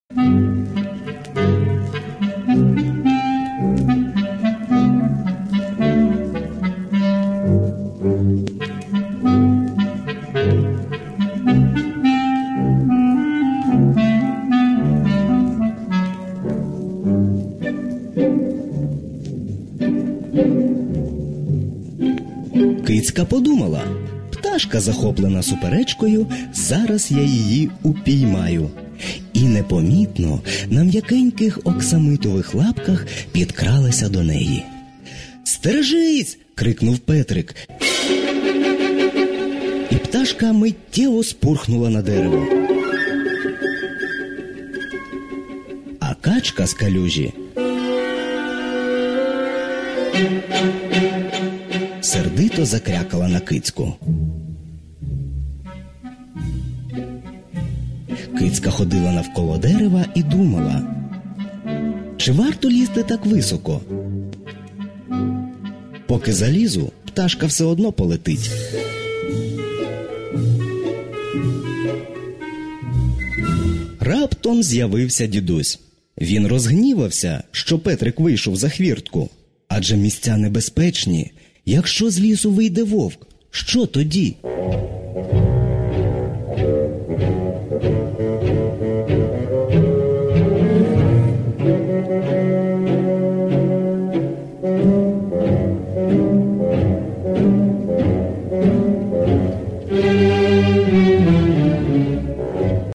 Симфонічна казка «Петрик і Вовк» (продовження)